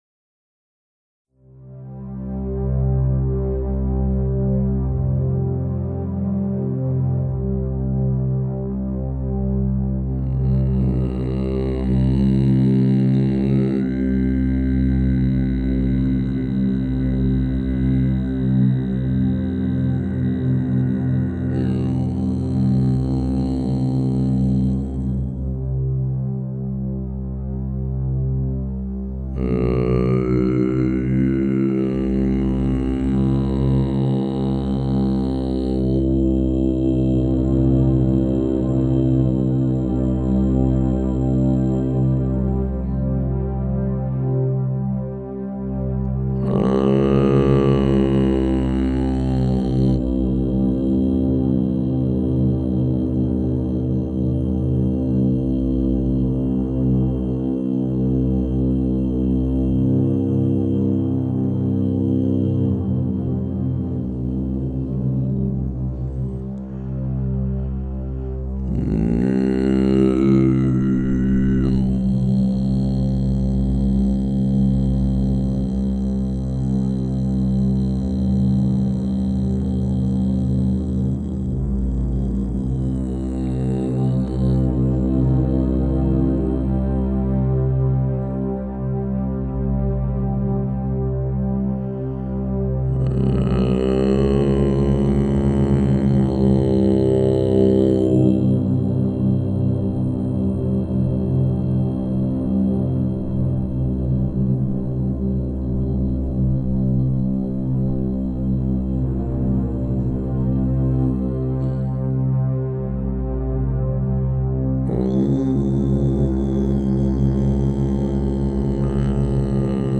en una cabana enmig del bosc, al pre Pirineu català
amb els teclats i la veu